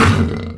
spawners_mobs_uruk_hai_hit.3.ogg